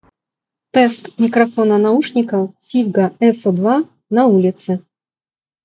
Качество передаваемого звука — высокого уровня, очень корректно работает шумоподавление. Звучание понятное и четкое.
В шумных условиях:
sivga-ulica-rus1.mp3